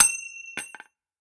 nut_impact_08.ogg